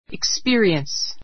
experience 中 A2 ikspí(ə)riəns イ クス ピ (ア)リエン ス 名詞 経験 , 体験 learn by [from] experience learn by [ from ] experience 経験から学ぶ ✓ POINT 一般 いっぱん 的に「経験」という時は × an experience, × experience s としない.